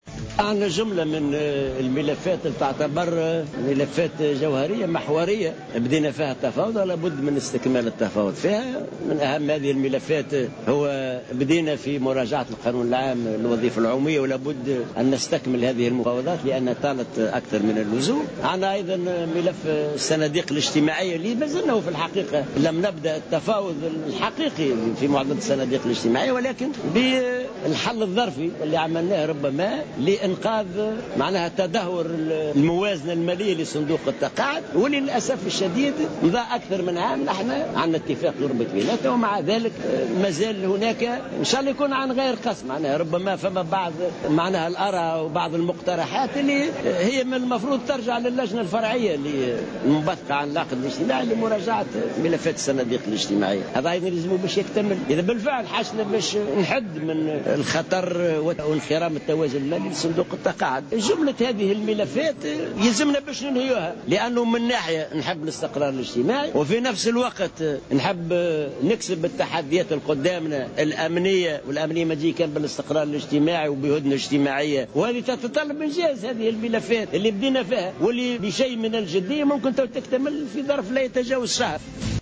وقال العباسي على هامش الندوة السنوية لقسم الوظيفة العمومية بالاتحاد العام التونسي للشغل التي انعقد اليوم بمدينة الحمامات إن المفاوضات في هذه الملفات "طالت أكثر من اللازم"، مشيرا إلى أن الحسم فيها، سيمكن من تحقيق الاستقرار الاجتماعي، وفق تعبيره.